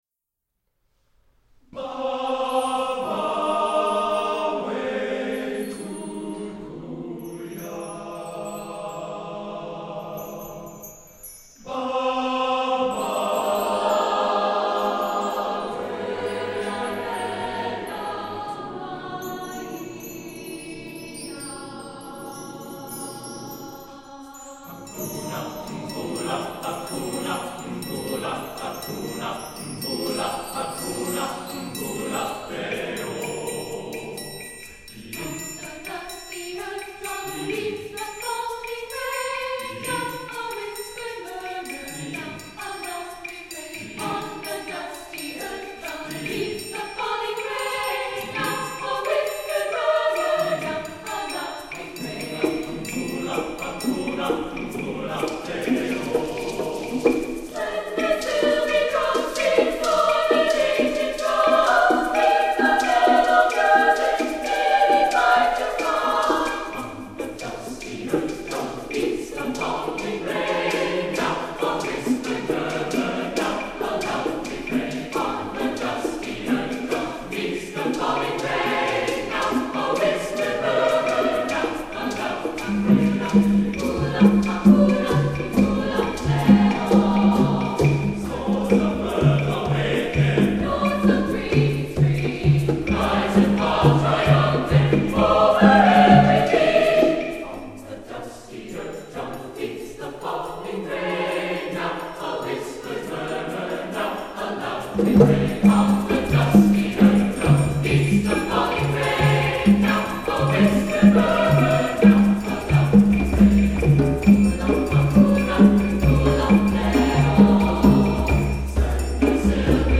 Voicing: Percussion Parts